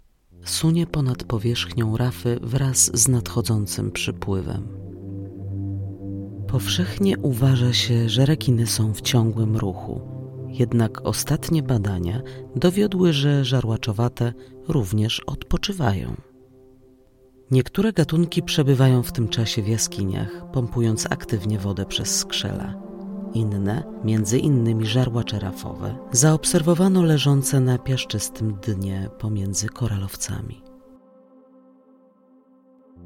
Female 30-50 lat
Strong alto - subdued (when seriousness is needed: IVR, corporate narration, voice-over, video explainers, sponsoring) or crazy (for advertising roles and audiobooks).
Demo lektorskie
Narracja do filmu